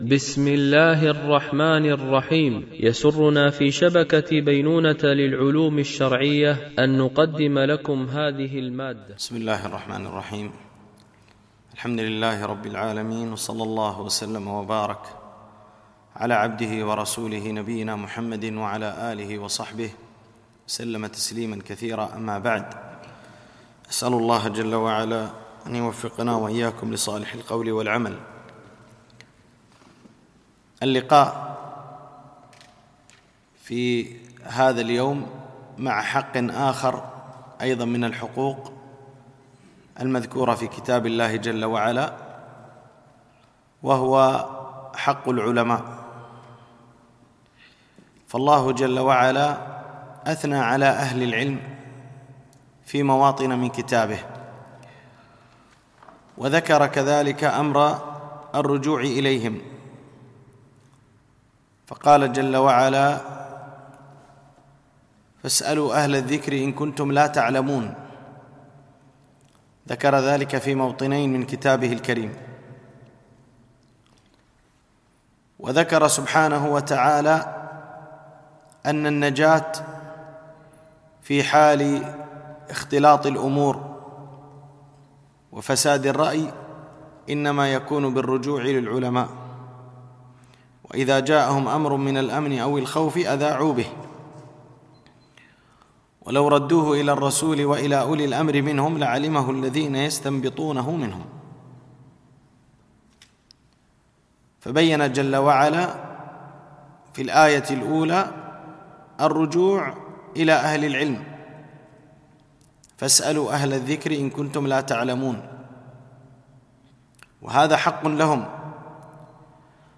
MP3 Mono 44kHz 96Kbps (VBR)